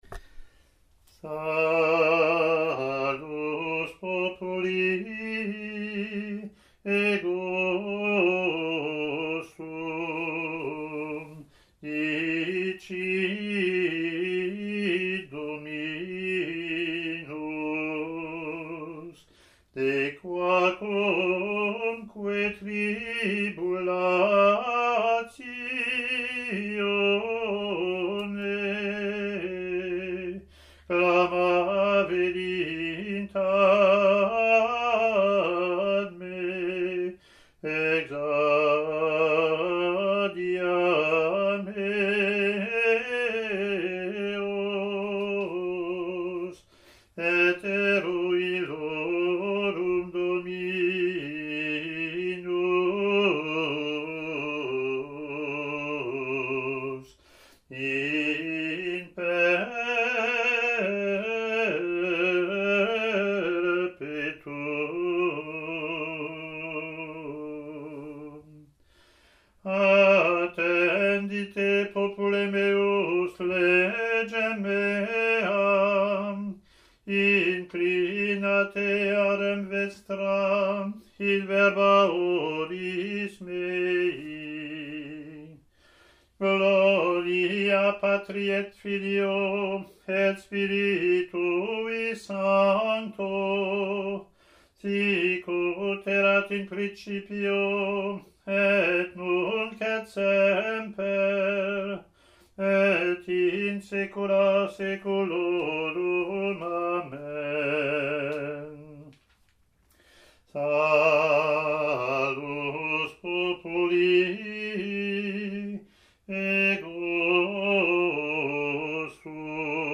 Latin antiphon and verse)